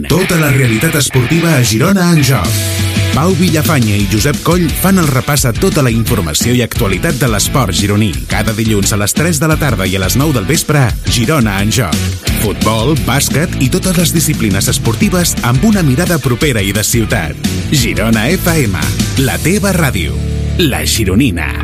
Promoció del programa
Esportiu